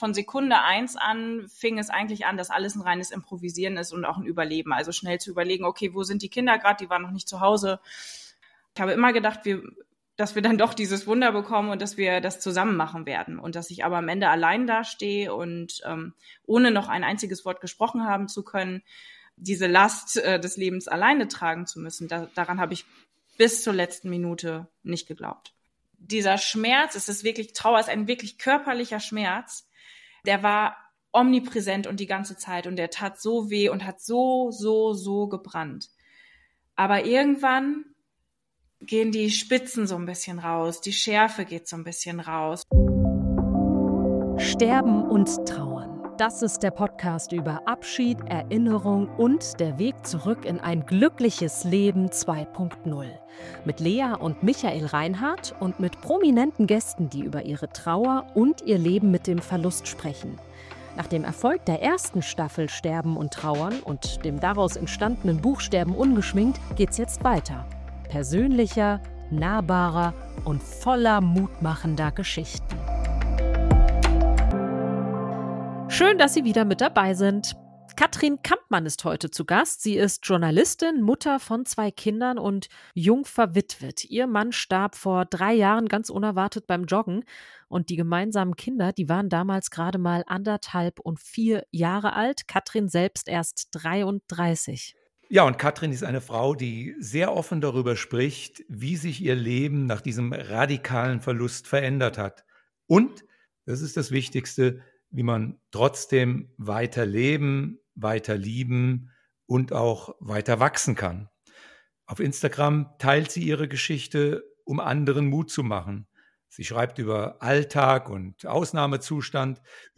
Ein bewegendes Gespräch über Liebe, Verlust, Verantwortung und Resilienz.